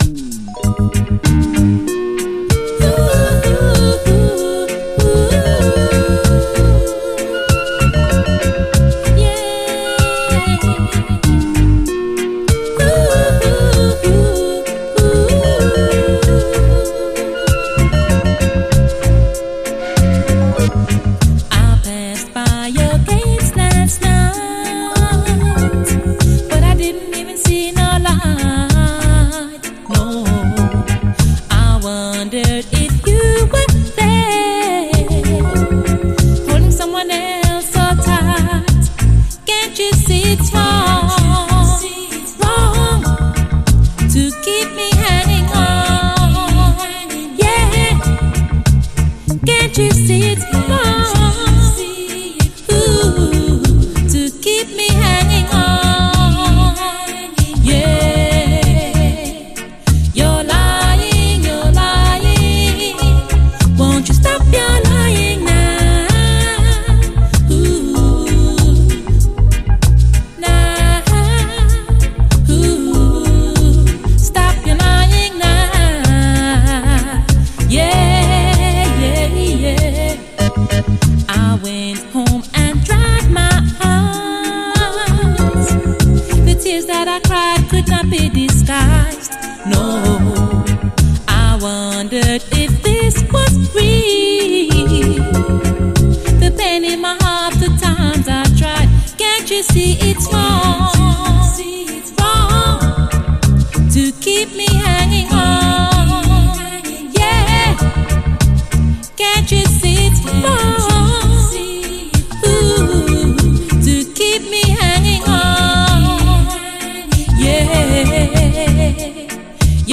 REGGAE
彼女の美声＆コーラスの魅力がストレートに伝わる、シンプルにして美しい一曲！